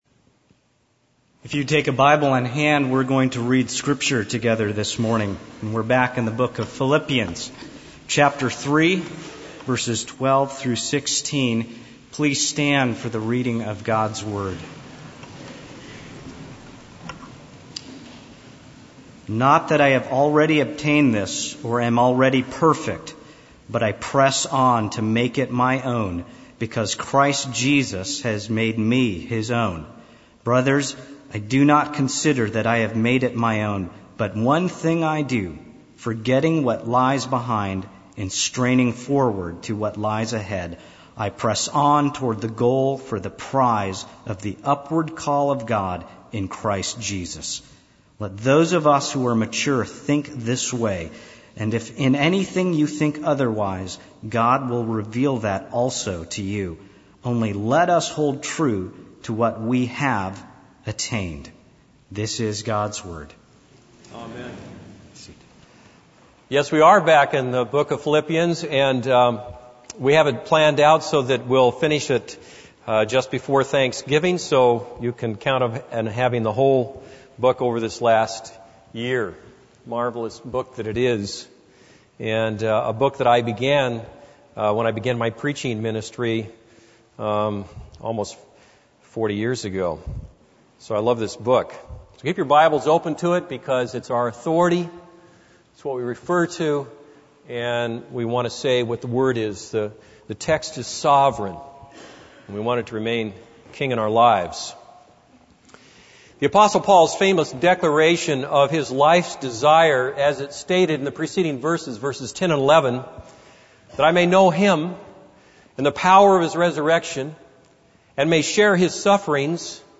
This is a sermon on Philippians 3:12-16.